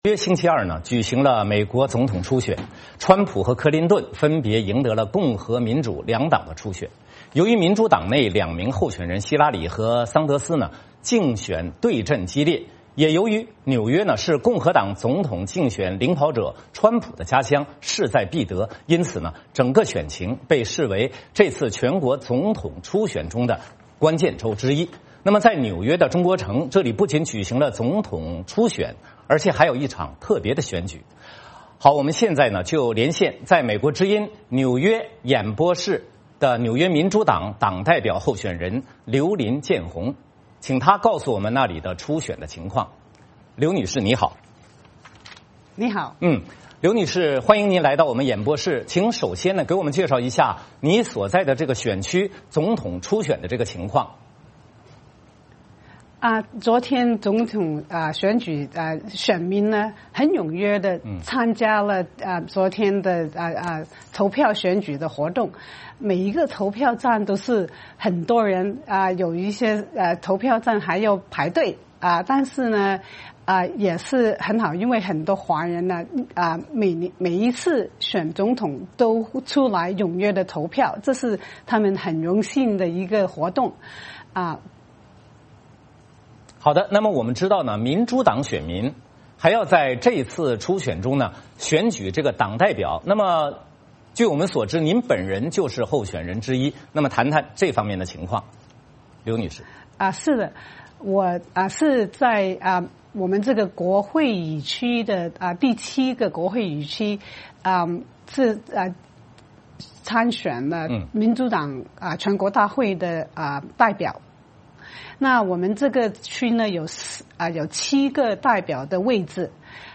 VOA连线: 克林顿和川普赢得纽约总统初选